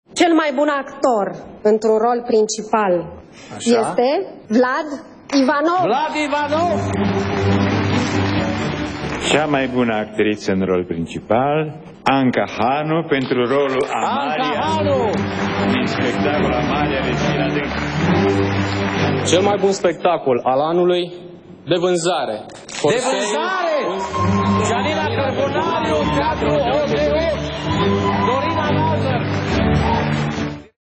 A XXIII-a ediţie a Galei Premiilor UNITER a avut loc ieri-seară, la Teatrul Național din București.